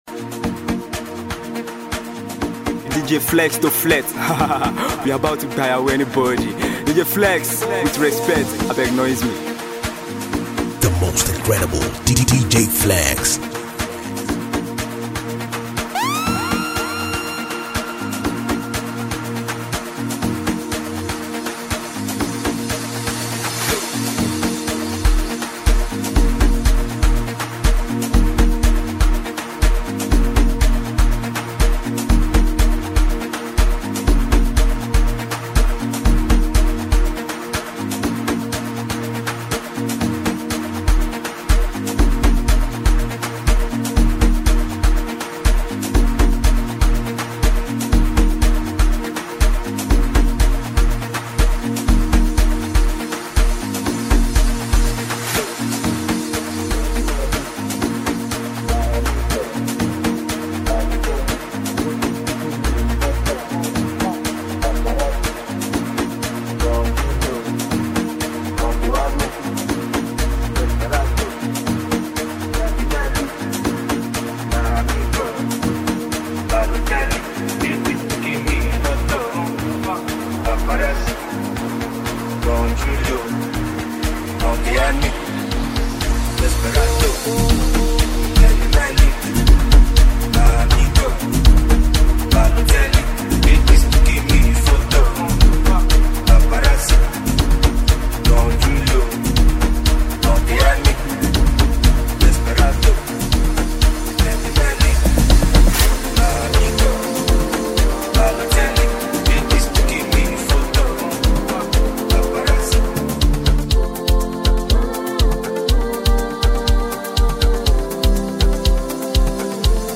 new dj mix